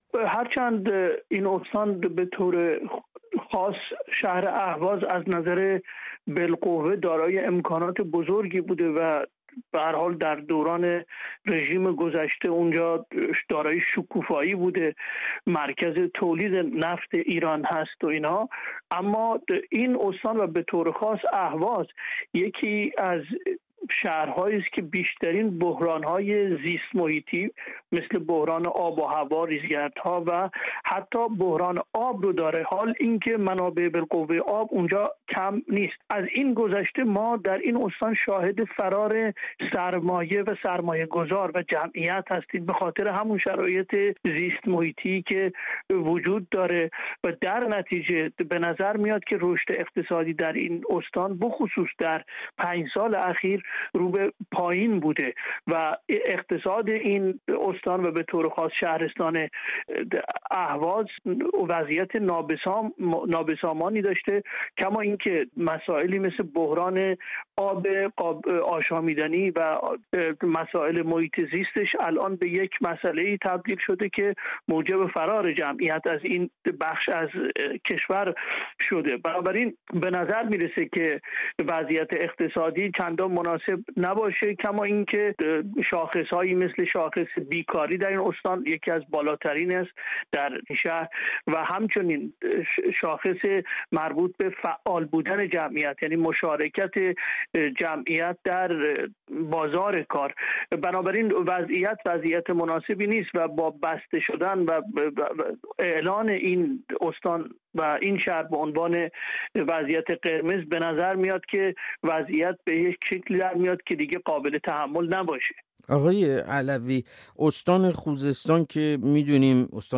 درخواستها برای تعطیلی کامل و قرنطینۀ استان خوزستان در حالی رد شده که مسائل و مشکلات اقتصادی و معیشتی از دلایل عمدۀ آن عنوان شده است. گفت‌وگوی